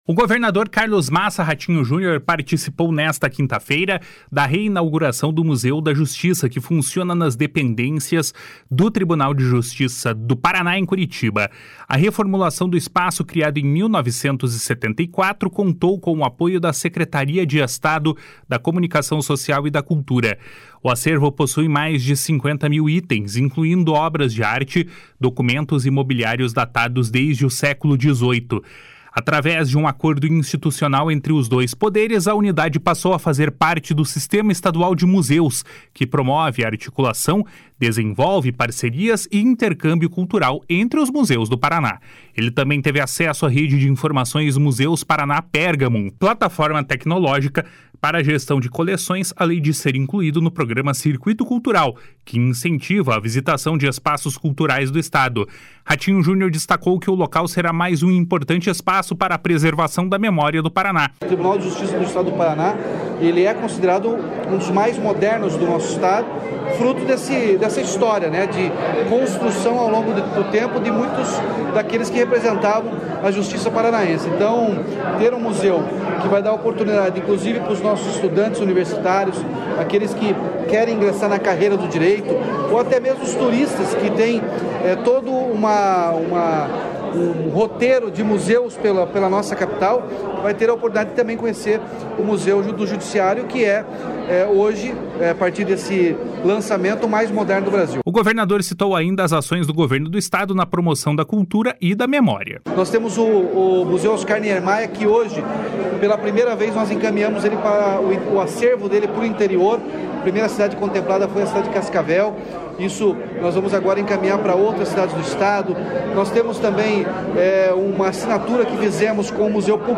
Ratinho Junior destacou que o local será mais um importante espaço para a preservação da memória do Paraná.